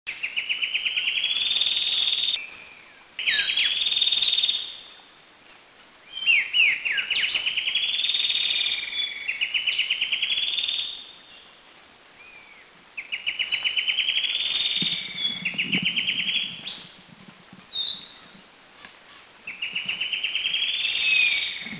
B2A_Rufous-frontedTailorbirdBohol210_SDW.mp3